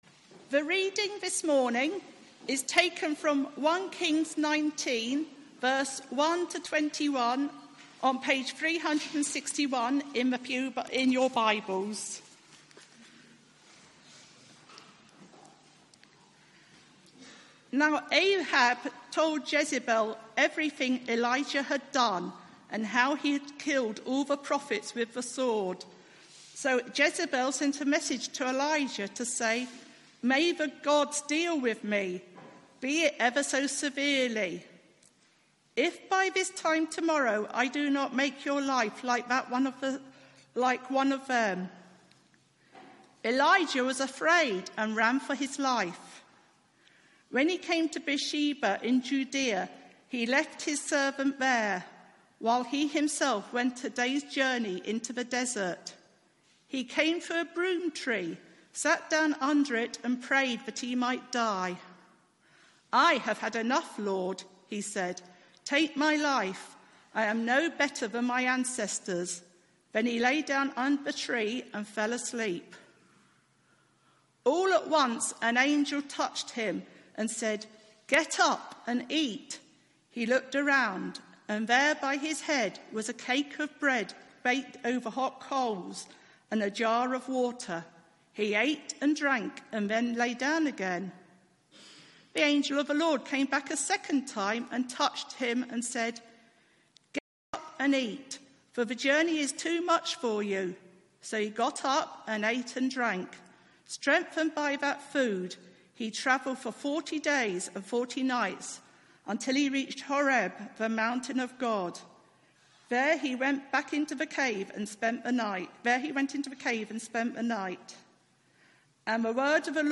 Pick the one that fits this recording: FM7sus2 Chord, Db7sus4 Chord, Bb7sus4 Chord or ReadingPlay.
ReadingPlay